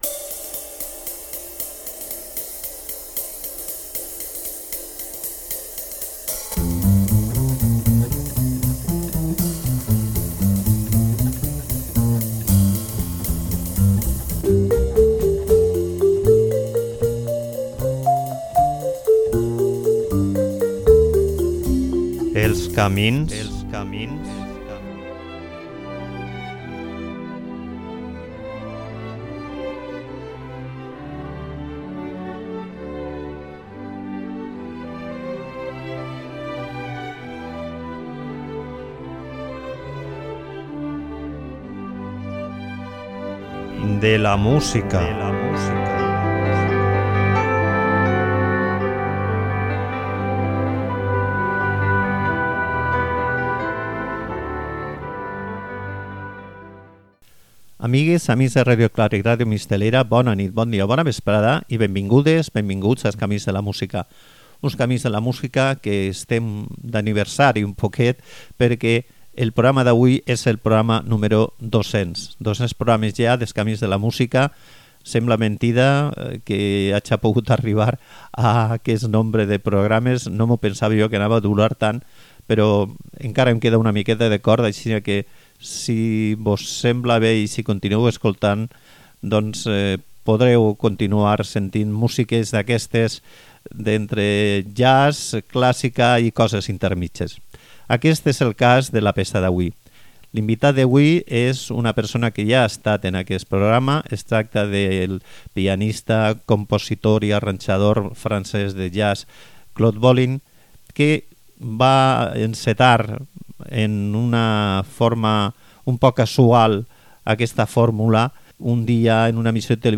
Suite per a Orquestra de Cambra i Trio de jazz